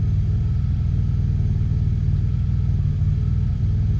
rr3-assets/files/.depot/audio/Vehicles/i6_02/i6_02_idle.wav
i6_02_idle.wav